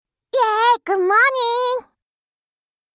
📌 Cheerful & Western-style → A playful mix of English and Japanese, mimicking an exaggerated, enthusiastic “Good morning!” Often used jokingly or in upbeat conversations.